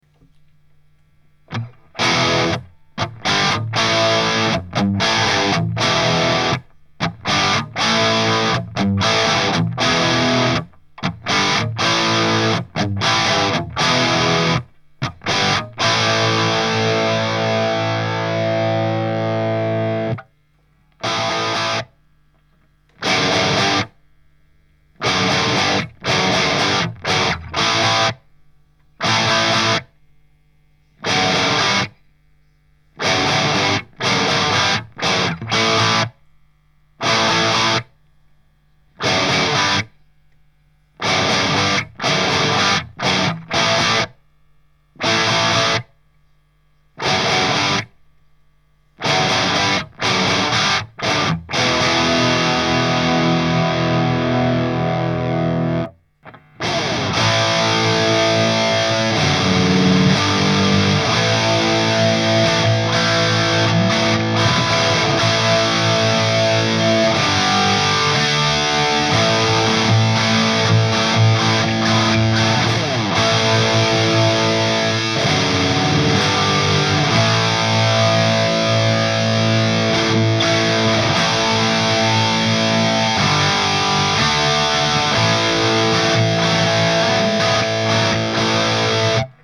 ������� �� ������ ���� (����� �������� ���������, ��, ��� "������" 4*12 � �30, SM57).